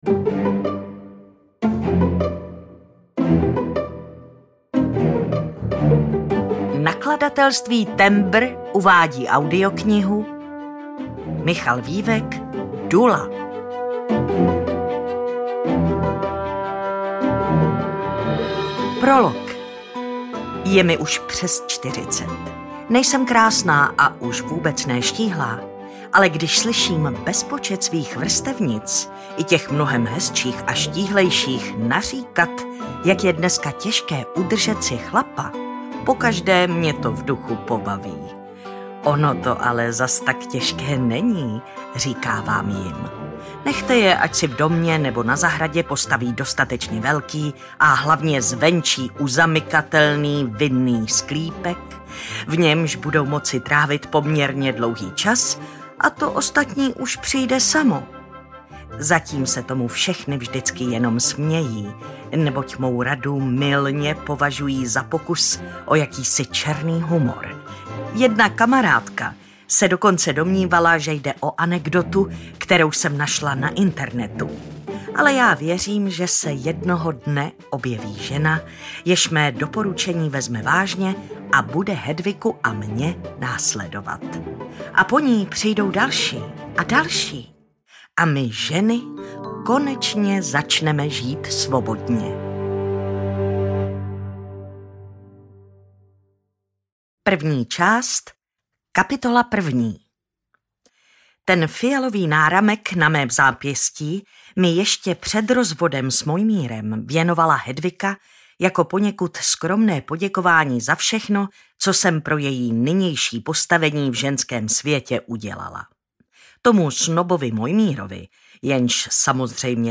Dula audiokniha
Ukázka z knihy